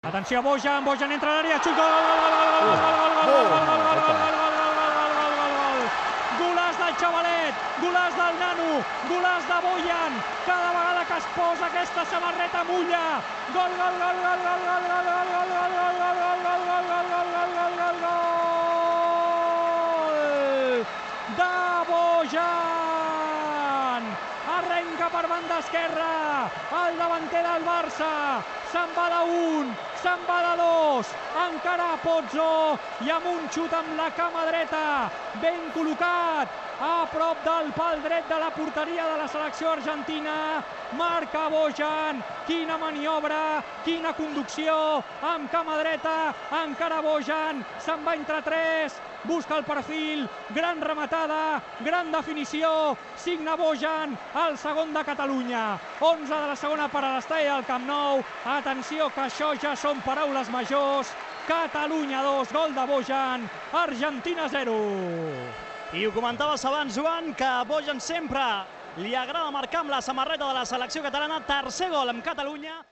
Narració dels gols del partit entre les seleccions de Catalunya i Argentina
Esportiu